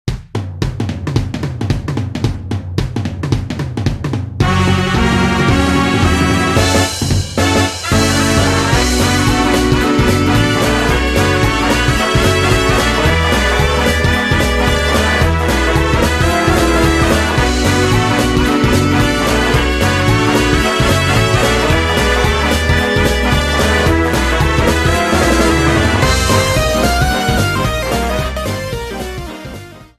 Applied fade-out You cannot overwrite this file.